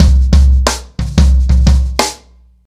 • 121 Bpm Breakbeat Sample C Key.wav
Free breakbeat sample - kick tuned to the C note.
121-bpm-breakbeat-sample-c-key-hs9.wav